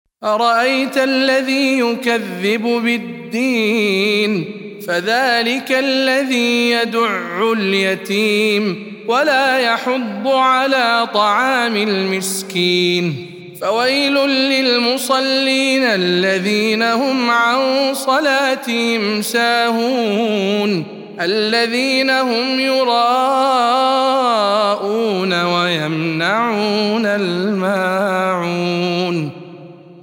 سورة الماعون - رواية خلف عن حمزة